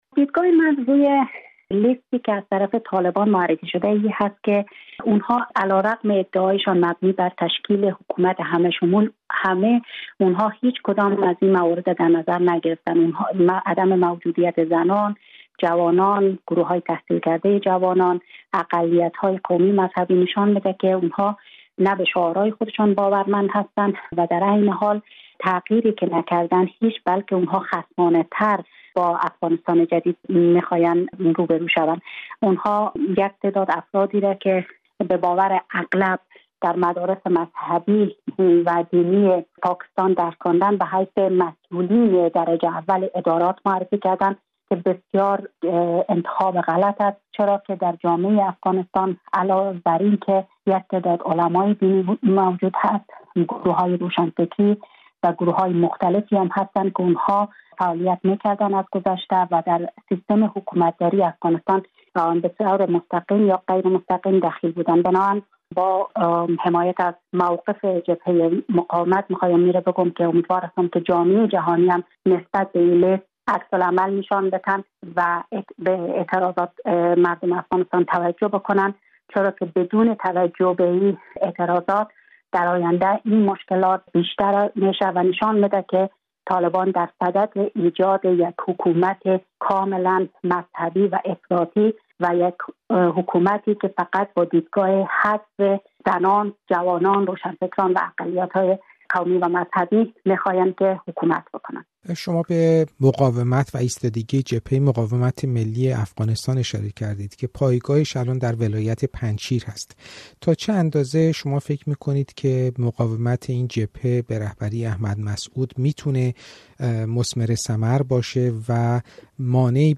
معرفی کابینه طالبان؛ گفت‌وگو با معاون وزیر دفاع پیشین افغانستان
منیره یوسف‌زاده، معاون وزیر دفاع در حکومت پیشین افغانستان، در گفت‌وگو با رادیوفردا به تحلیل اقدام طالبان در تشکیل دولت موقت پرداخته است.